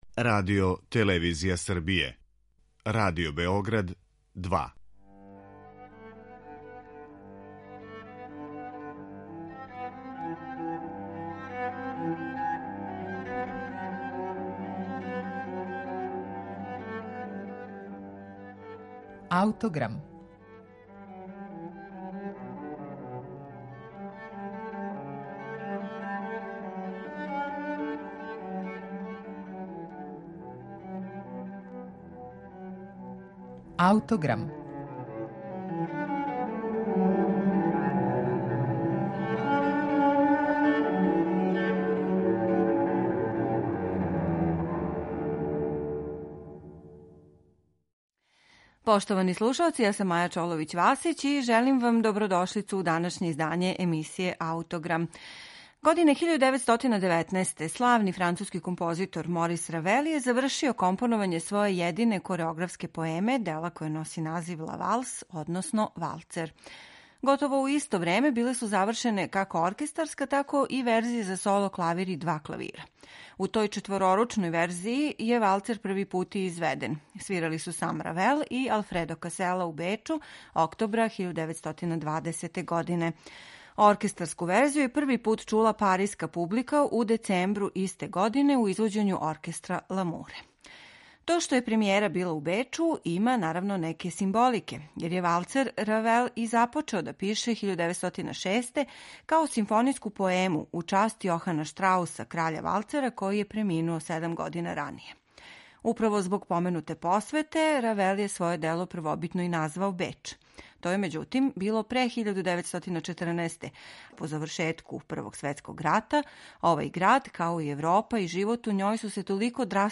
Валцер (који постоји у верзијама за соло, два клавира и оркестар) данас ћете слушати у интерпретацији Париског оркестра под управом Данијела Баренбојма.